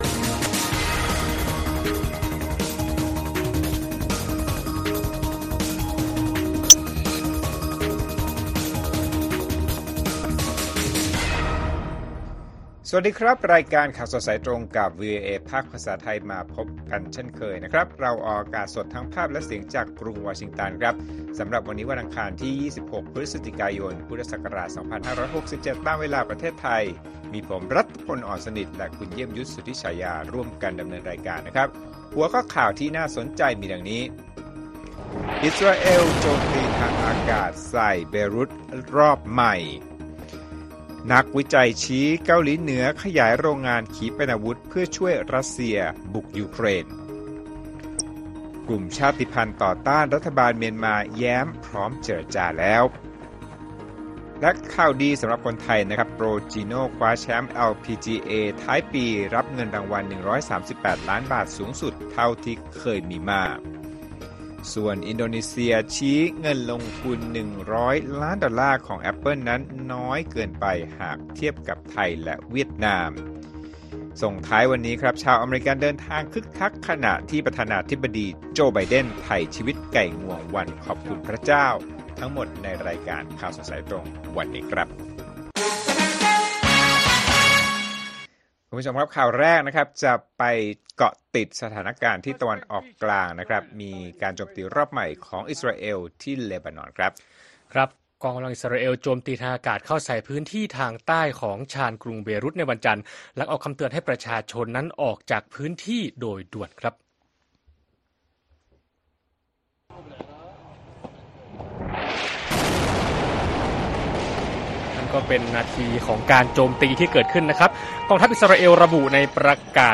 ข่าวสดสายตรงจากวีโอเอ อังคาร 26 พ.ย. 2567